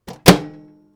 Microwave Door Close 3 Sound
household